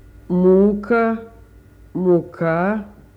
9.3.3.a: russisk [ˈmukə muˈka]